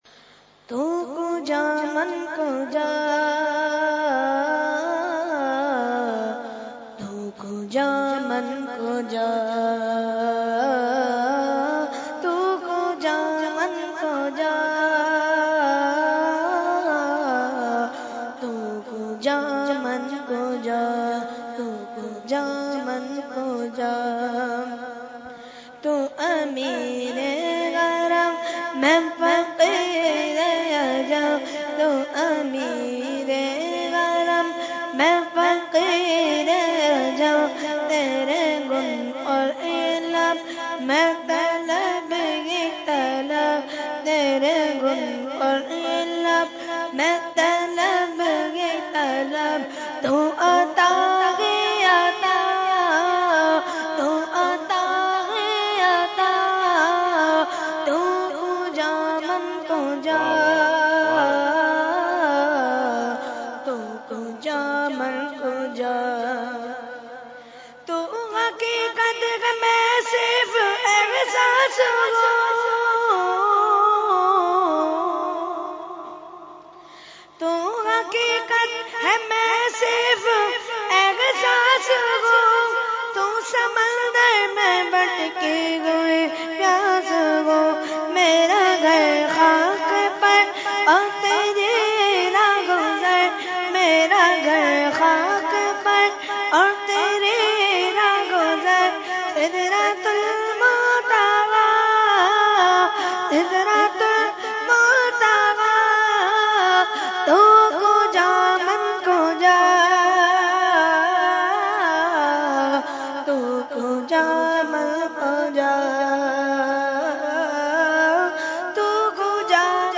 Category : Naat | Language : UrduEvent : Urs Makhdoome Samnani 2017